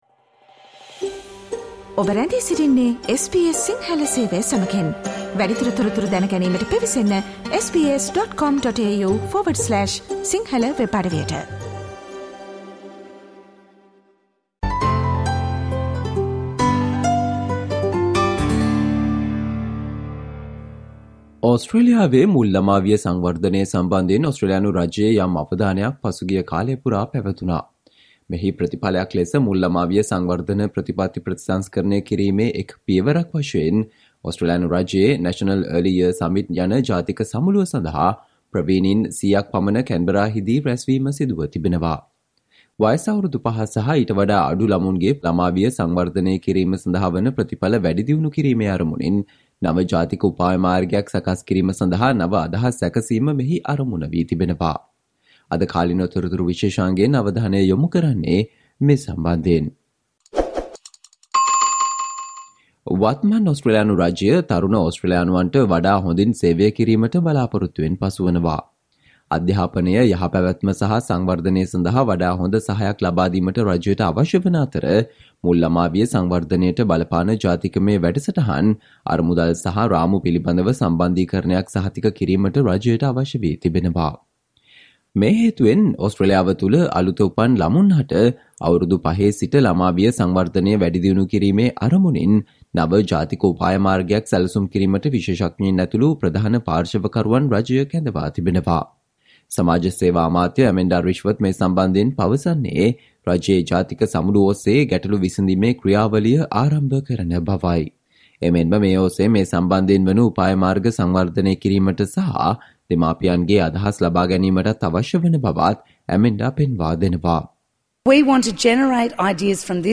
Today - 20 February, SBS Sinhala Radio current Affair Feature on the steps taken from the Australian Government to shape the future of early childhood education